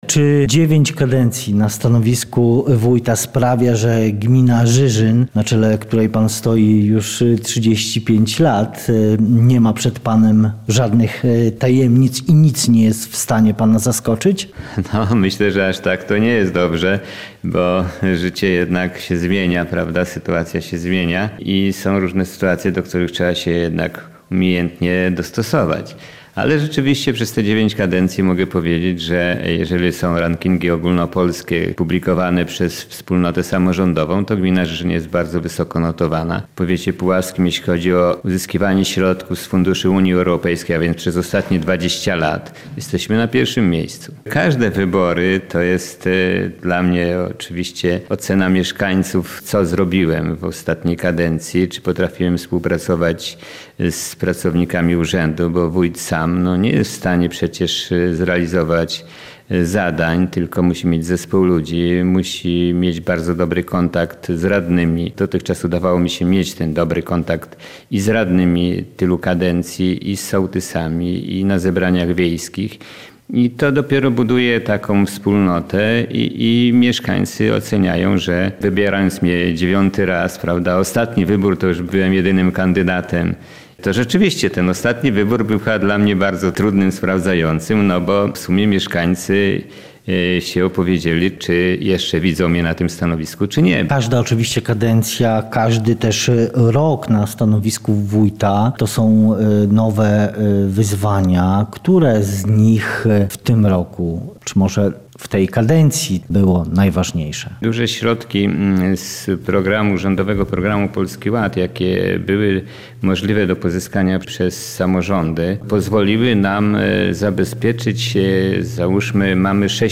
O najważniejszych dokonaniach w ciągu ostatnich miesięcy i planach na kolejny rok z Andrzejem Bujkiem rozmawiał nasz reporter